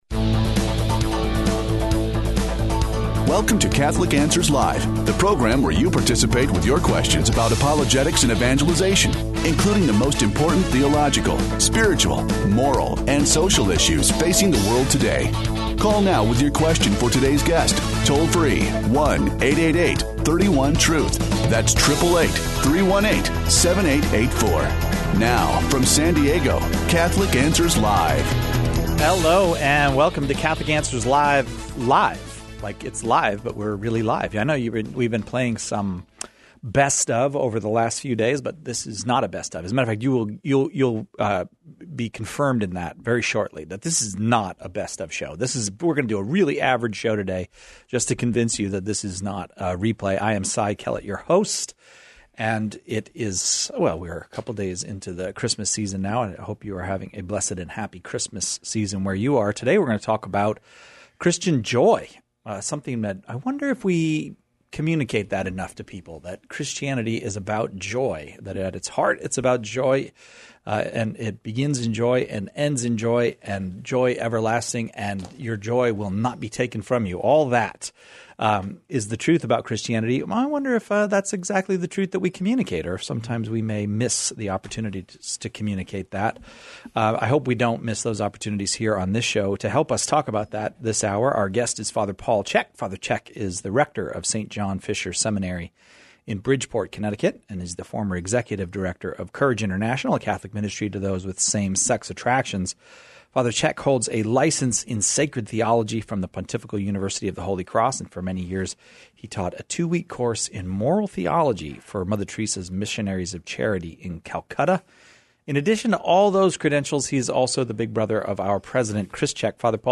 Our priestly panel discusses the meaning of Christian joy, and offers insights into how joy transforms individuals and societies.